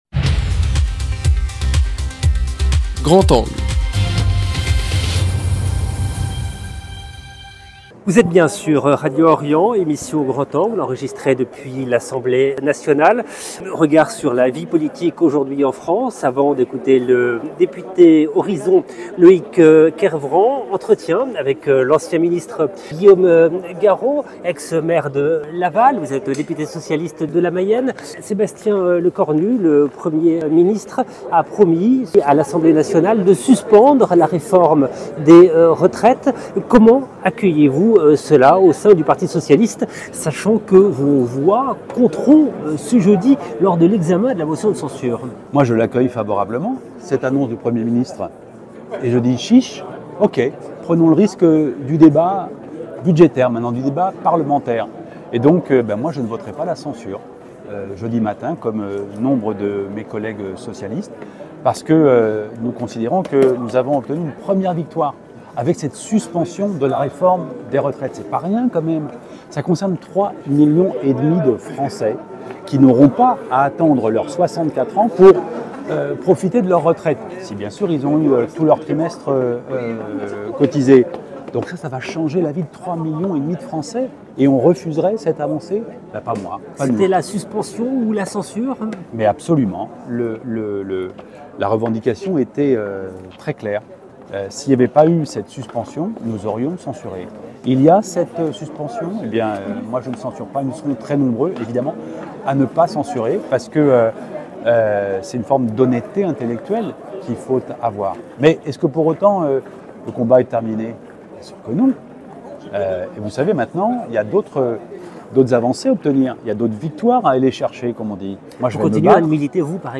Pour en parler : Guillaume Garot, député PS, et Loïc Kervran, député Horizons. La suspension de la réforme des retraites, proposée par le Premier ministre et soutenue par une partie de la gauche, suffira-t-elle à désamorcer la crise ?
enregistrée depuis l’Assemblée nationale. 0:00 9 min 29 sec